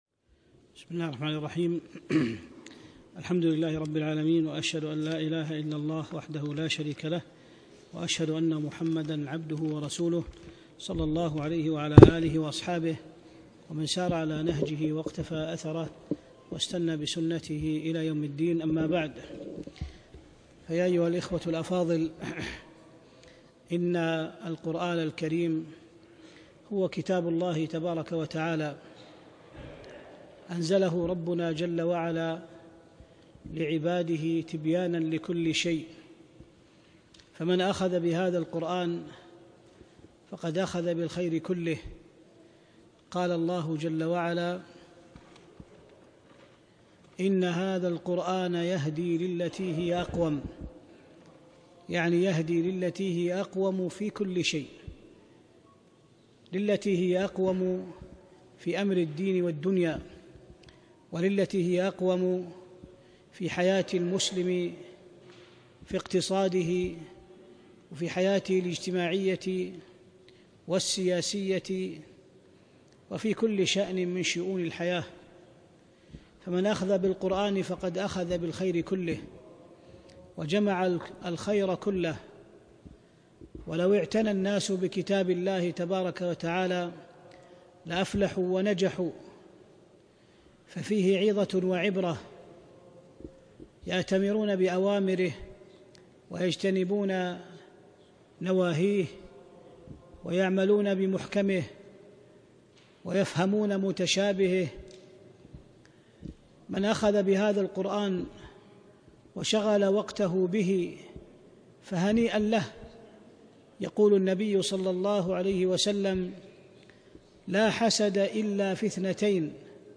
محاضرة رائعة - الأمثال القرآنية وسبل فهمها